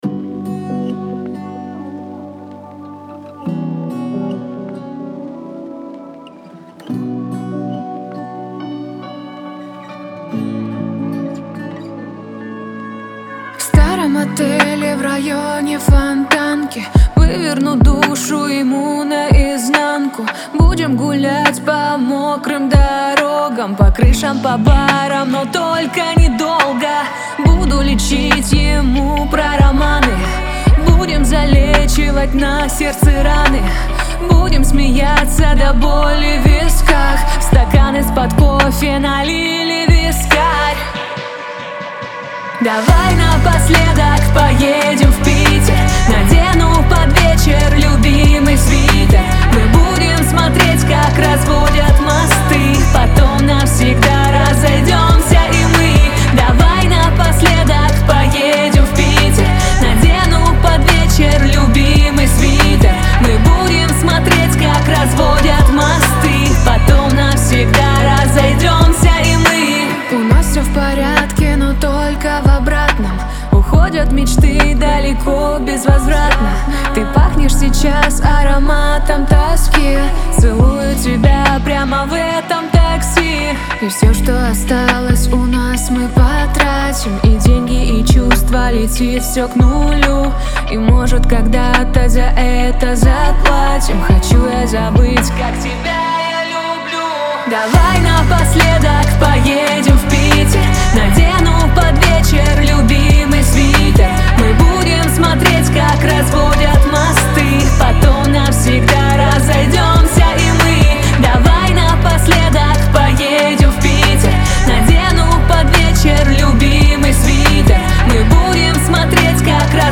Лирика , грусть